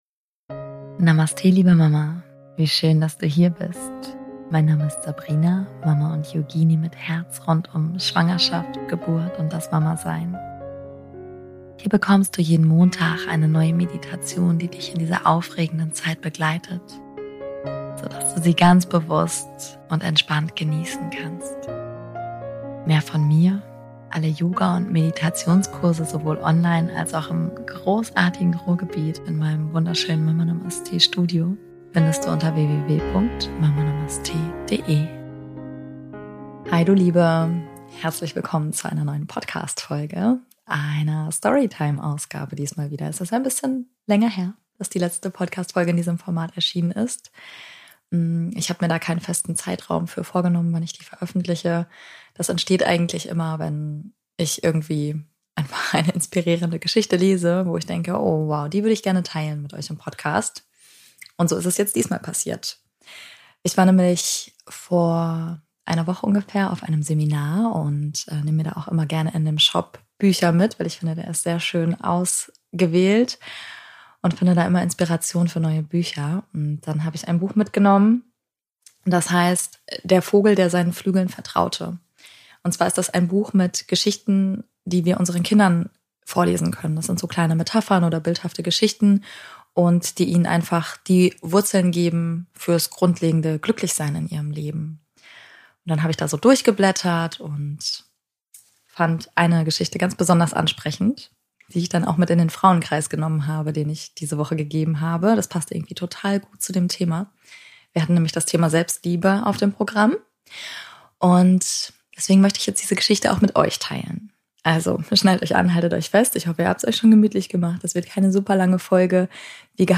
Es gib eine neue Story Time Folge - Diesmal lesen wir eine Geschichte aus dem Buch Der Vogel, der seinen Flügeln vertraute von Anjana Gill. Lass dich inspirieren zum Thema Selbstliebe und wieso wir uns als Mamas eigentlich immer miteinander vergleichen müssen.